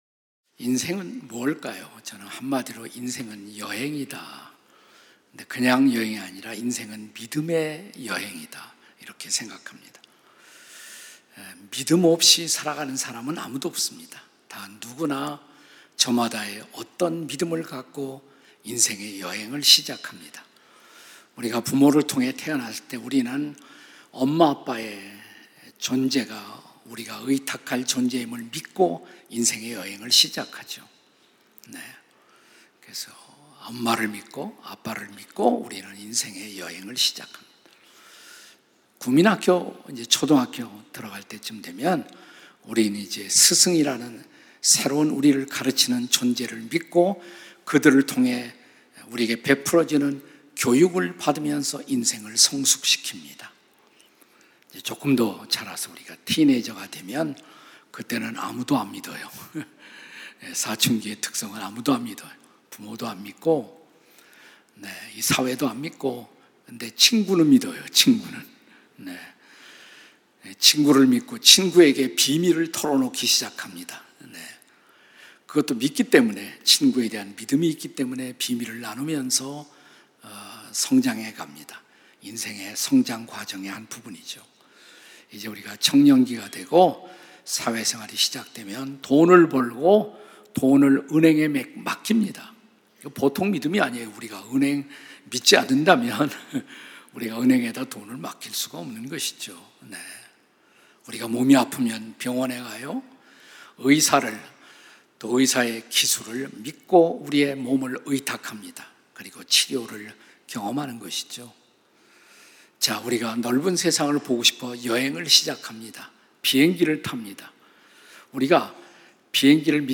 설교 : 주일예배 히브리서 - (14) 당신의 믿음, 구원에 이르는 믿음인가요?